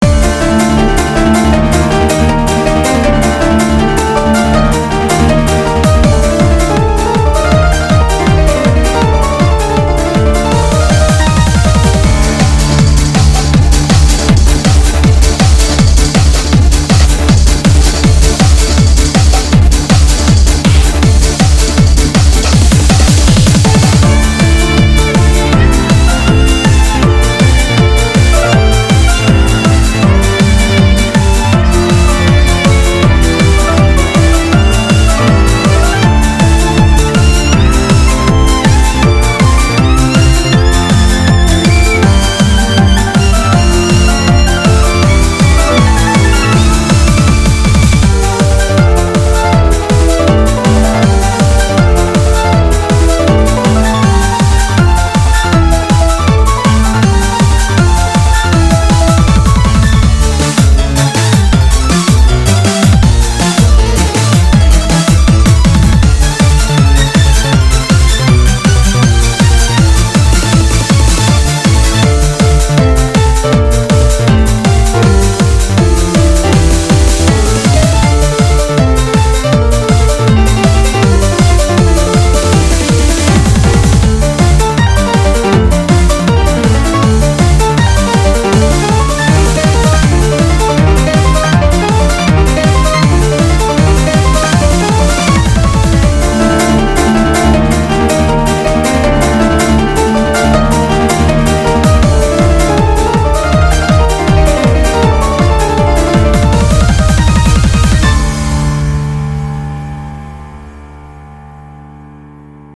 바이올린과 피아노 선율이 멋져 올렸습니다.